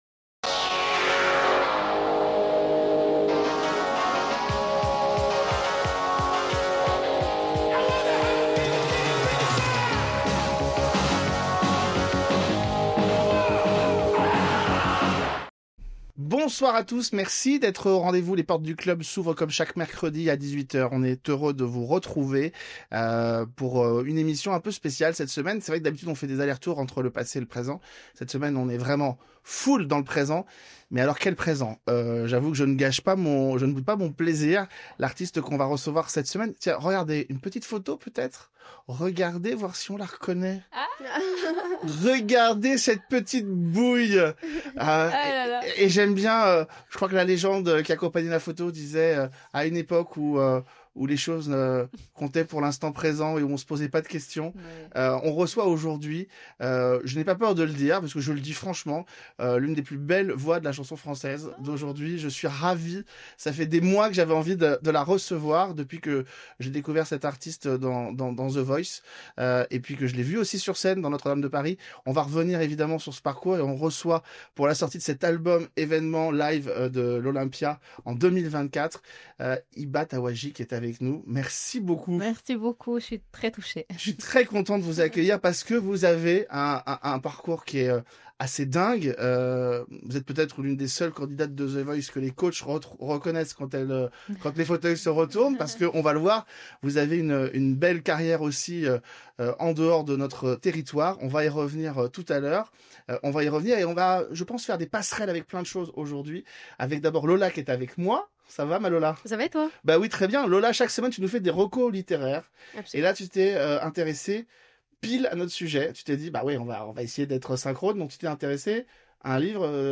Deux femmes inspirantes sont cette semaine les invitées du Club.